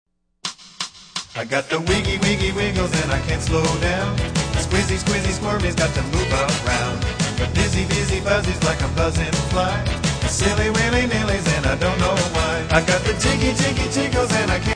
Gradually slow down as the music gets slower.